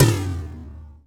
TOM MID S04L.wav